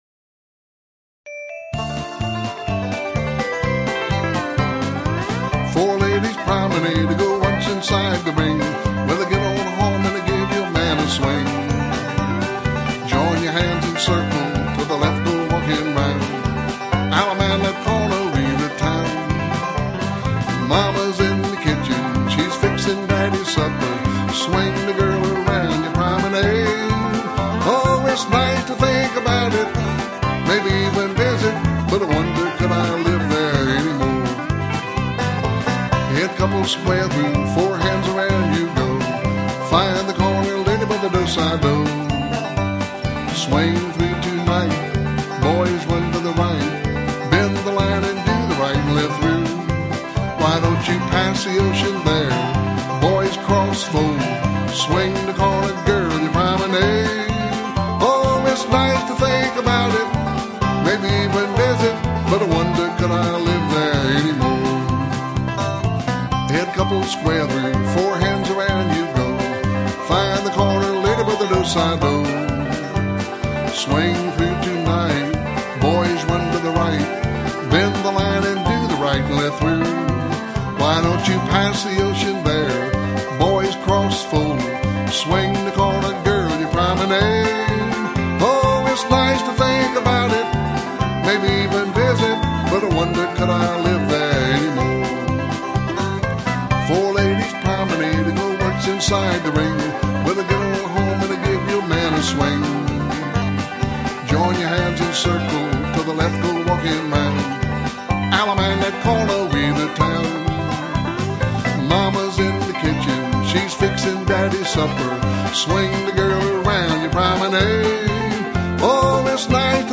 Singing Calls